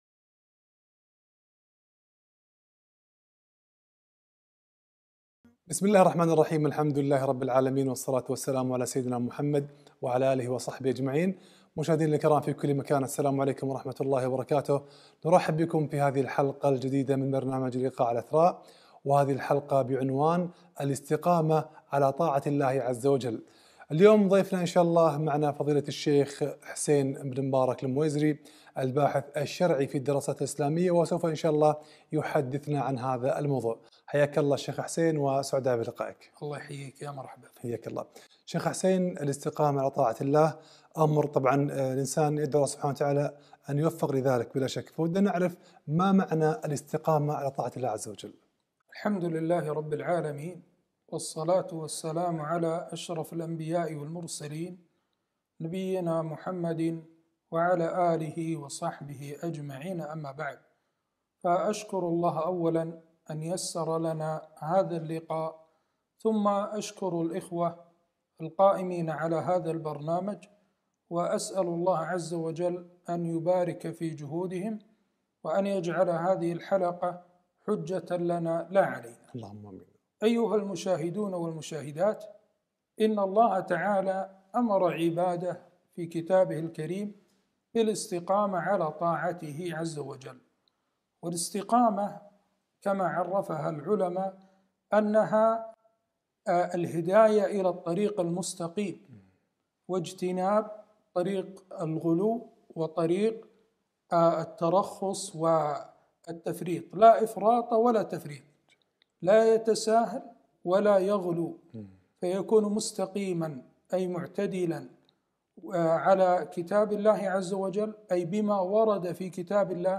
الاستقامة على طاعة الله - لقاء على قناة إثراء